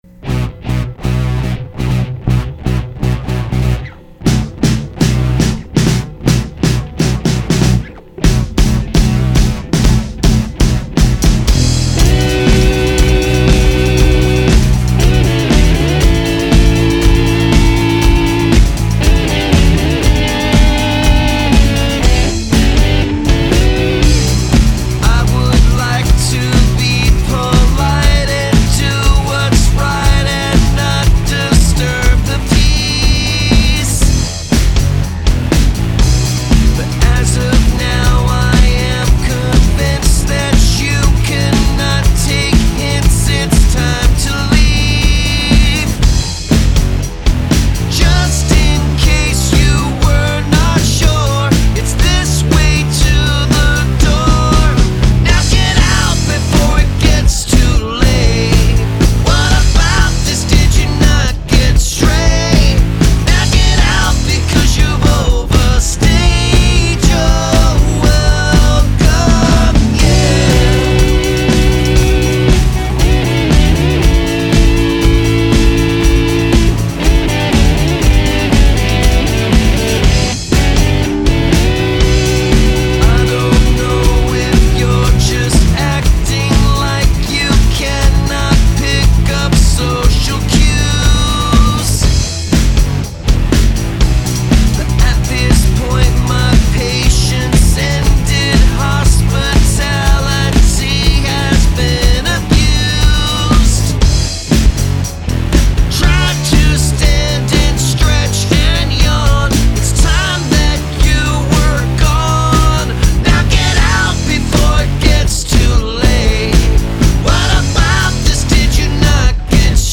Another huge rocker, those fast hats set a driving pace.
The chorus will be stuck in my head forever.